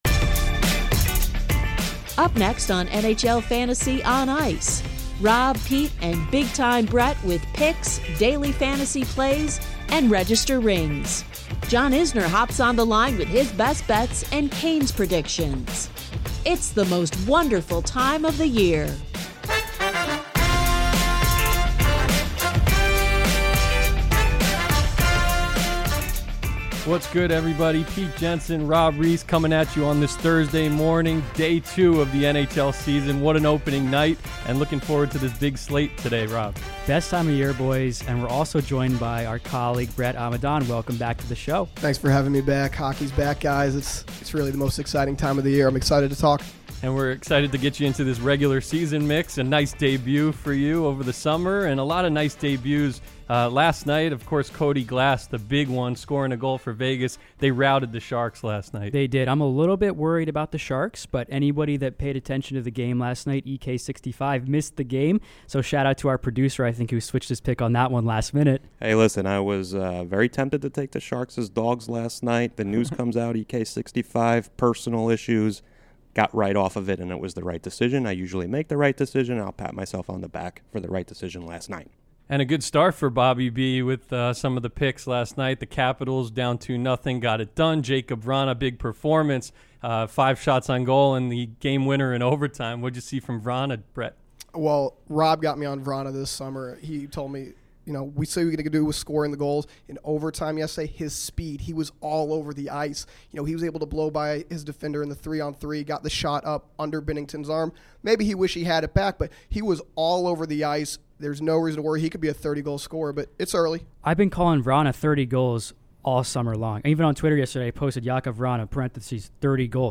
John Isner hops on the line to talk about his best bets for the season and Carolina Hurricanes.